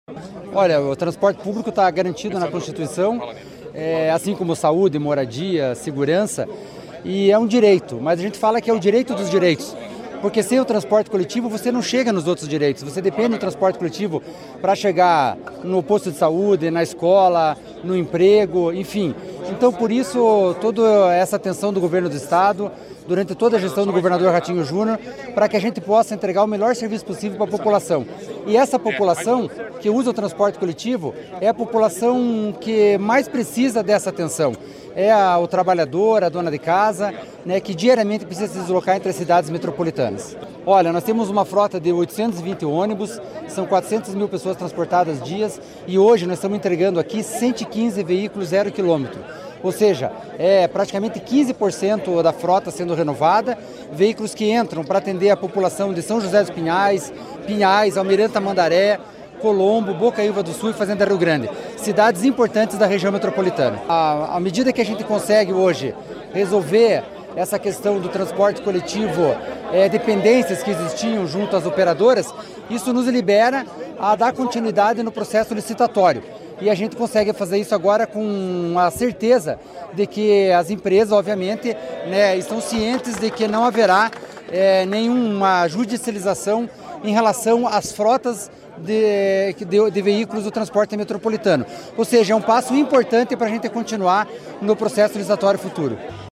Sonora do diretor-presidente da Amep, Gilson Santos, sobre a entrega de 115 ônibus para o transporte coletivo da Região Metropolitana de Curitiba